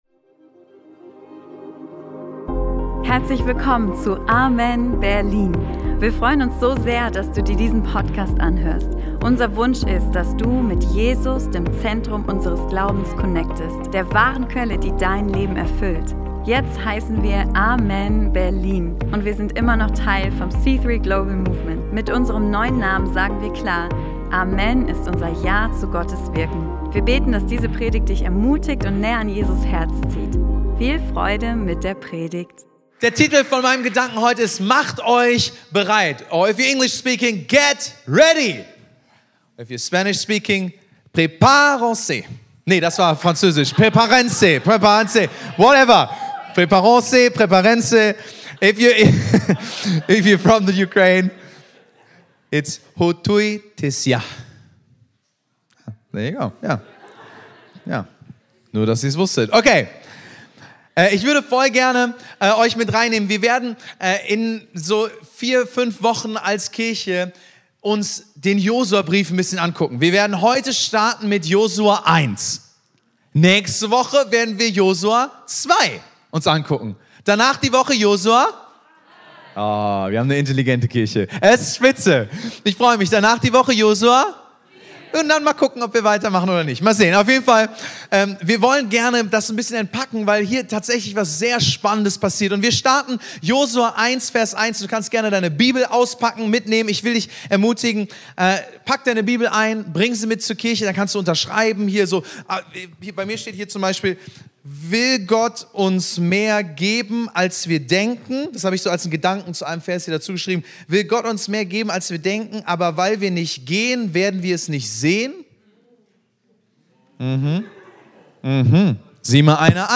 In dieser Predigt nehmen wir dich mit in die Geschichte von Josua – einen Moment, in dem Gott sein Volk auffordert, sich bereit zu machen, obwohl die Umstände alles andere als günstig sind.